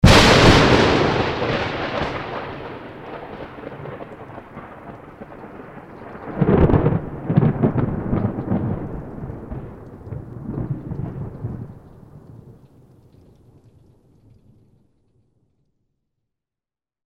ambientthunder.mp3